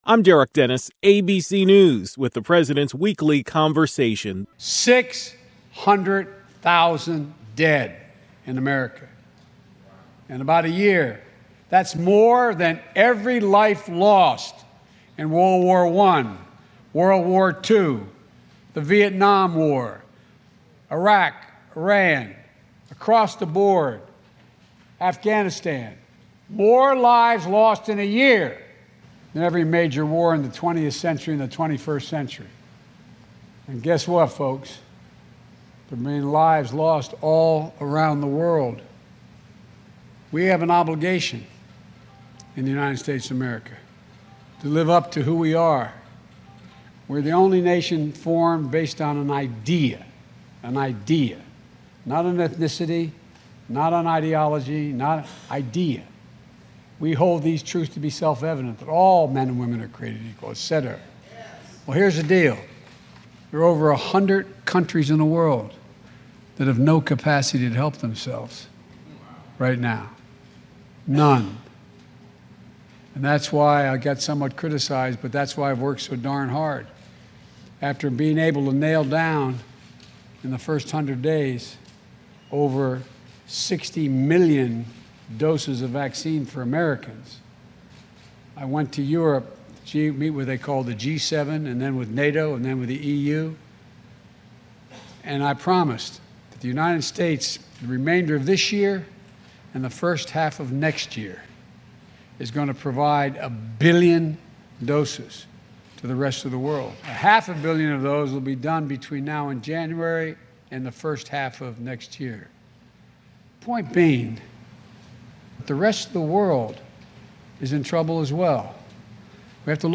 President Joe Biden was in Raleigh, North Carolina, delivering a message on the importance of getting vaccinated.